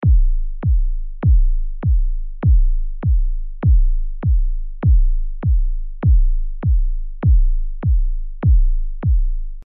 دانلود آهنگ سمپل ضربان قلب از افکت صوتی انسان و موجودات زنده
جلوه های صوتی
برچسب: دانلود آهنگ های افکت صوتی انسان و موجودات زنده دانلود آلبوم صدای ضربان قلب انسان از افکت صوتی انسان و موجودات زنده